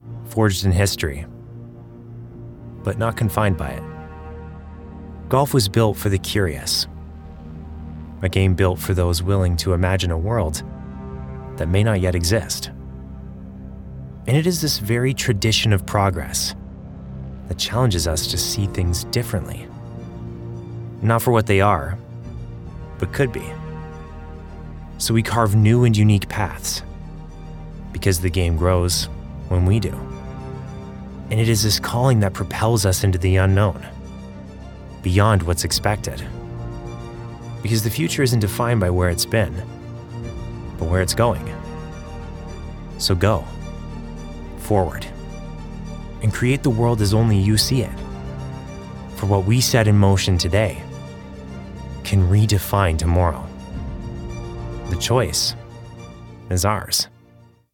Commercial Samples